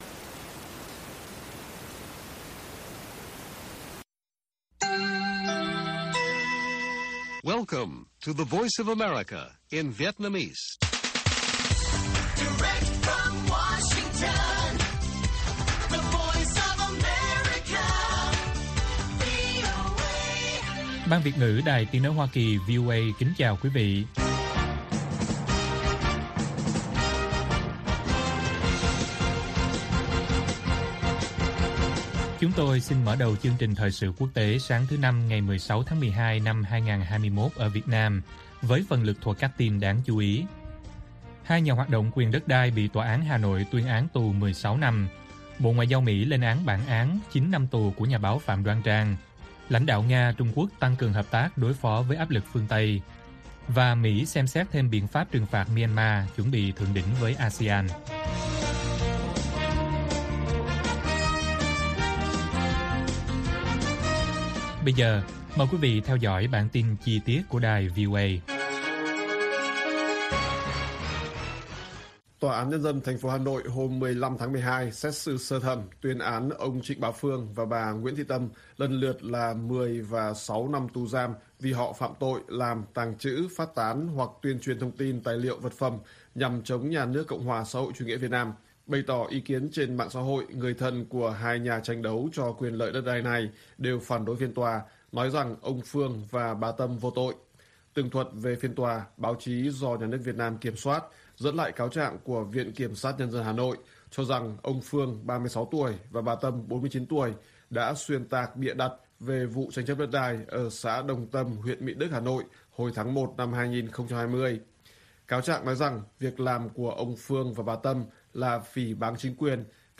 Bản tin VOA ngày 16/12/2021